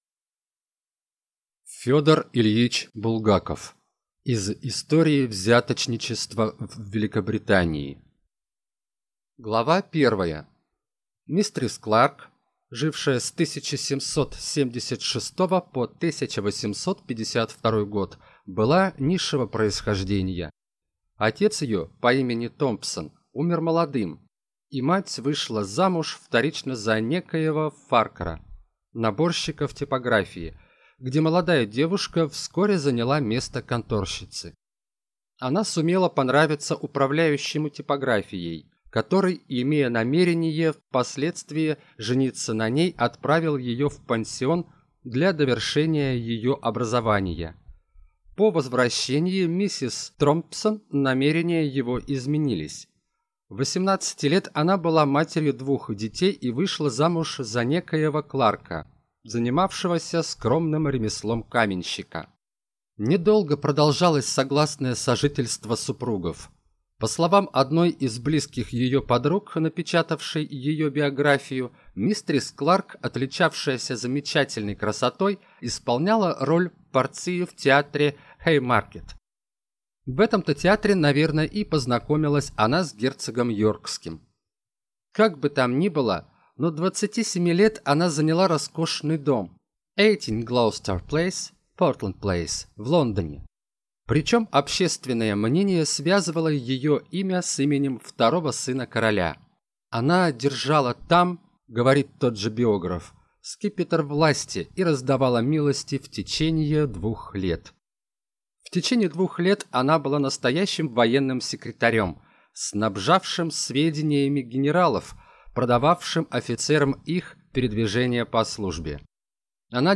Аудиокнига Из истории взяточничества в Великобритании | Библиотека аудиокниг